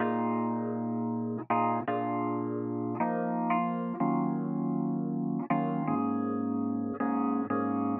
17 Elpiano PT4.wav